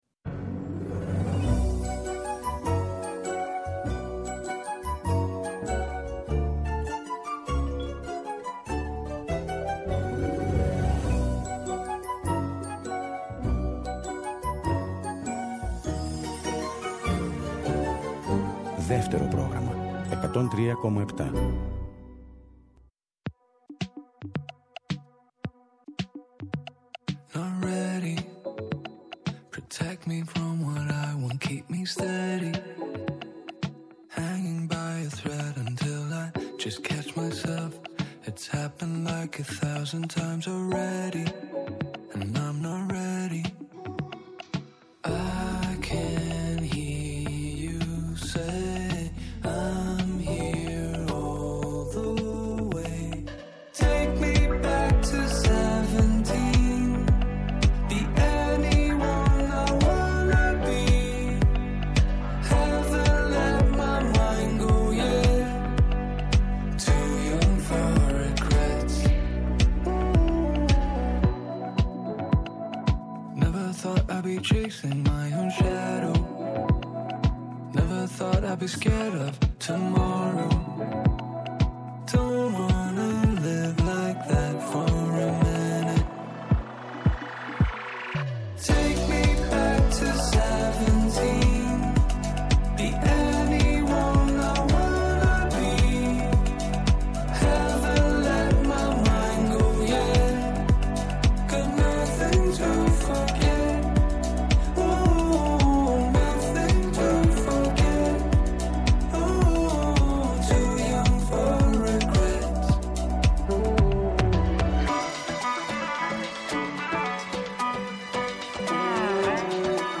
με τα πιο ξεχωριστά νέα τραγούδια του μήνα που φεύγει.